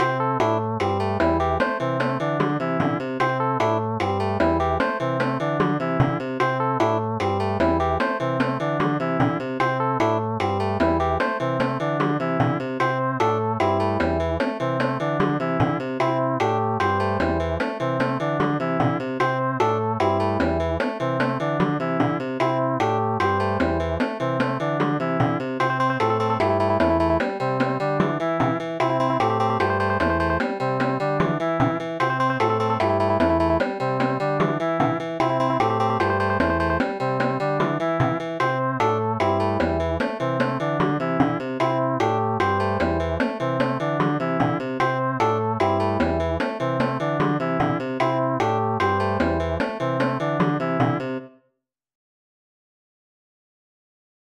Sounds like it'd be good for an item shop or a bar.